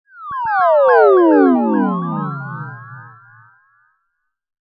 Sci-Fi Teleportation Electronic Frequency Sweep Sound Effect
A high-quality synthetic sci-fi sound effect with a rapid, oscillating electronic frequency sweep.
This immersive electronic sweep delivers a powerful, otherworldly energy perfect for sci-fi projects.
Genres: Sound Logo
Sci-fi-teleportation-electronic-frequency-sweep-sound-effect.mp3